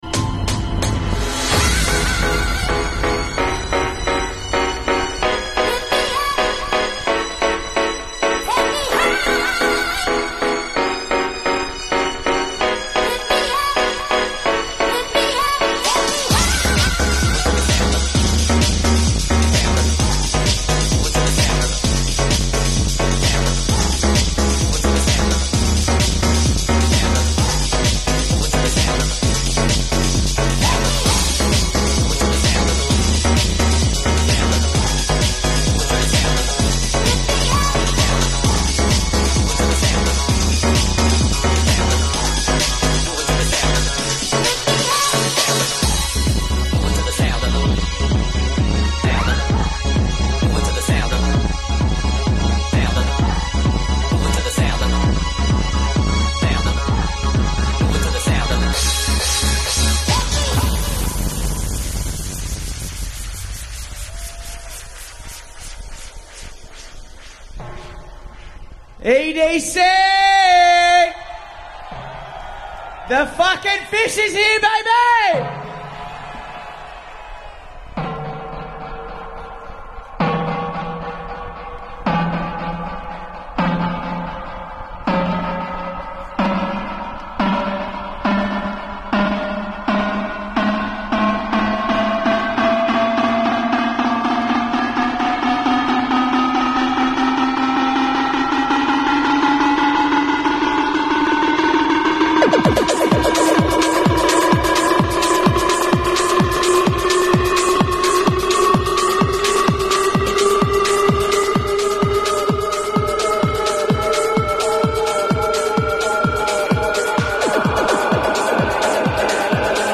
The set was canceled after 20 minutes due to strong wind